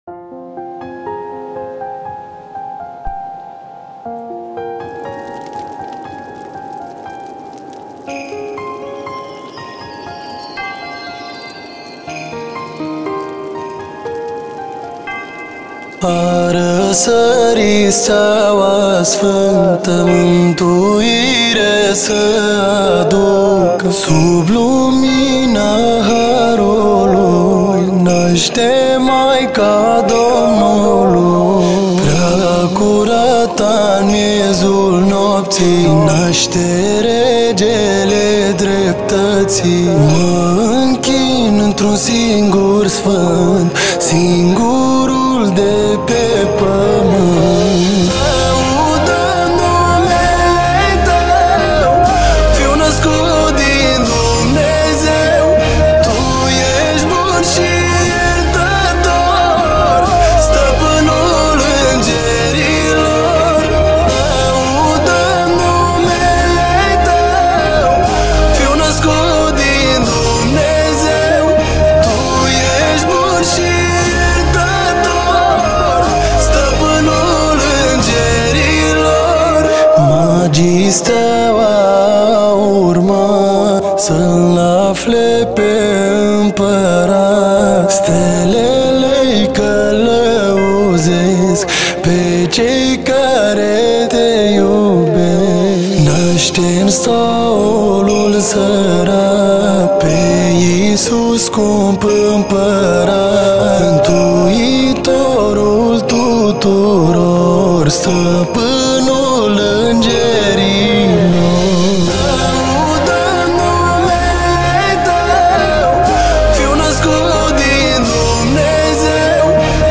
Colinde de Craciun